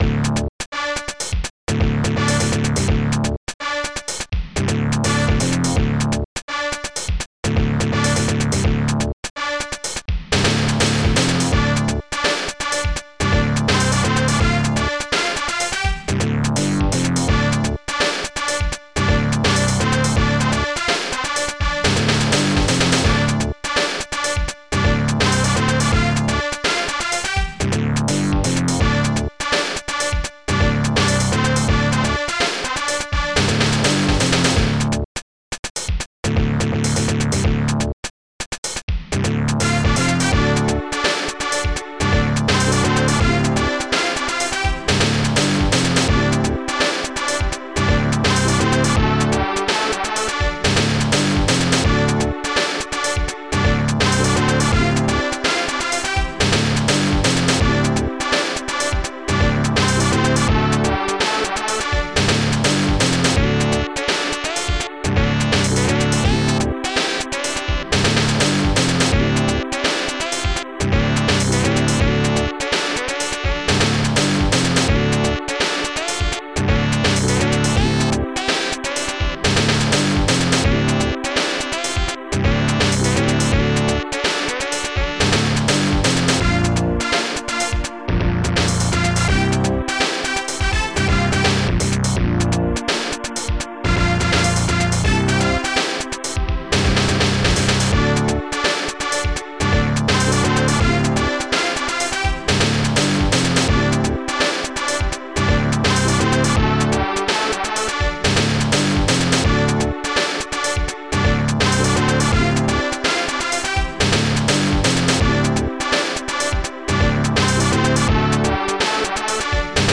Protracker and family
st-15:castlesax
st-12:acidbass
st-11:ronkhihat
st-11:ronksnare
st-14:kydtrumpet